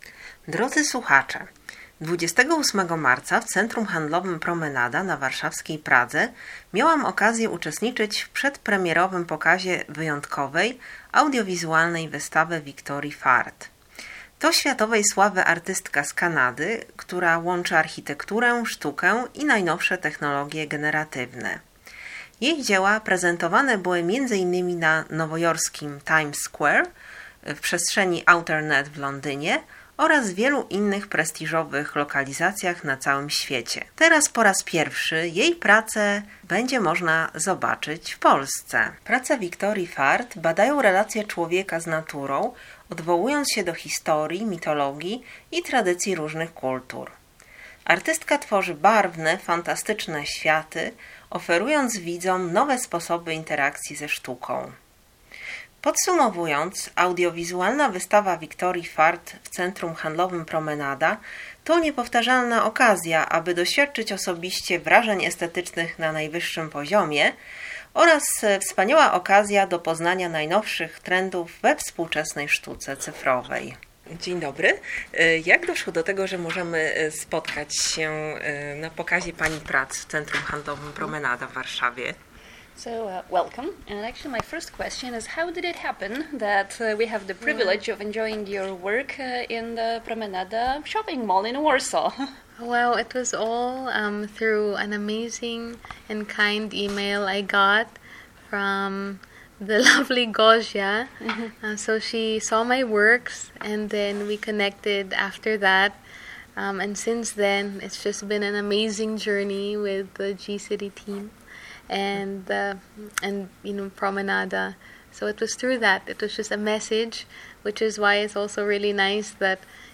Serdecznie zapraszamy Państwa do odsłuchu wywiadu, szczególnie, że na końcu rozmowy czeka Was niespodzianka. https